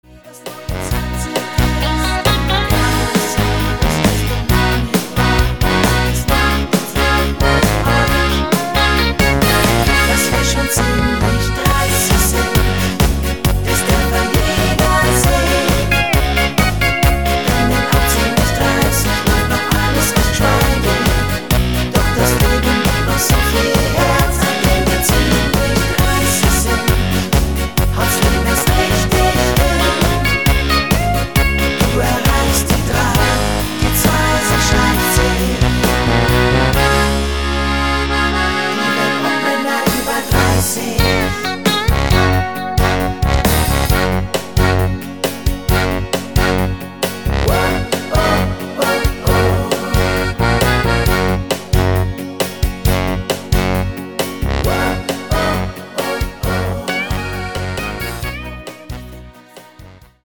So stellt man sich Volksmusik vor